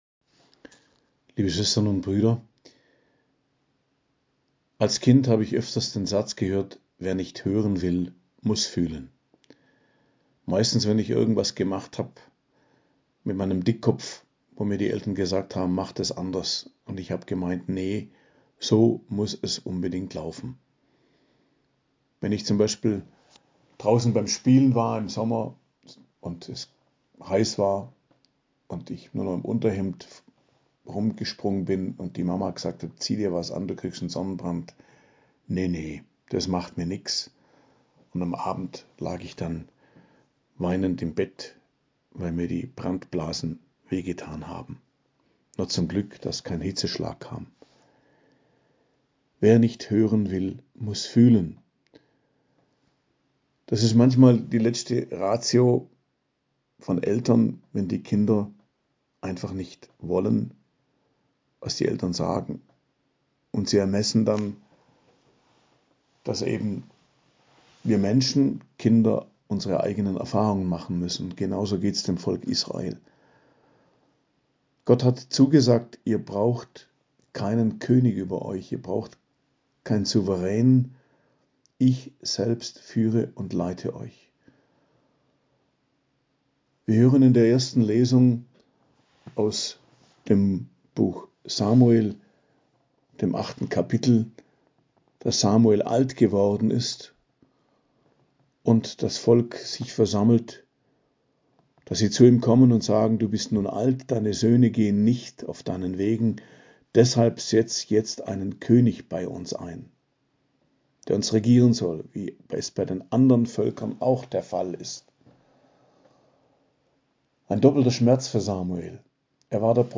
Predigt am Freitag der 1. Woche im Jahreskreis, 12.01.2024 ~ Geistliches Zentrum Kloster Heiligkreuztal Podcast